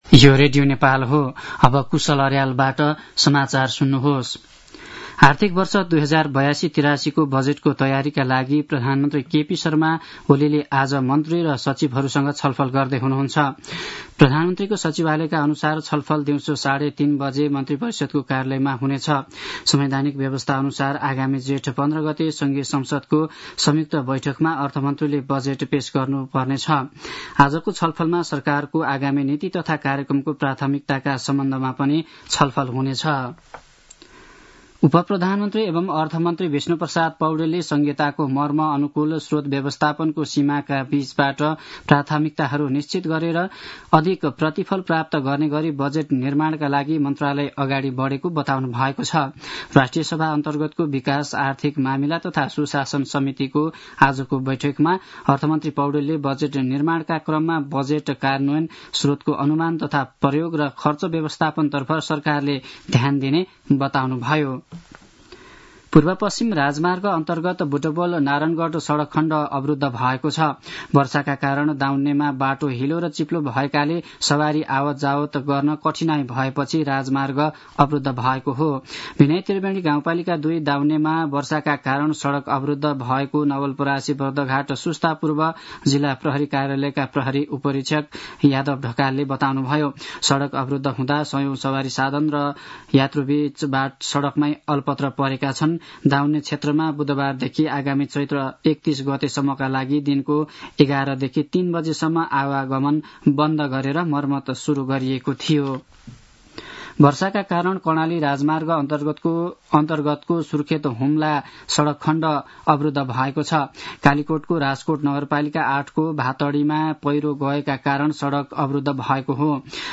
दिउँसो १ बजेको नेपाली समाचार : २८ चैत , २०८१
1-pm-news-1-3.mp3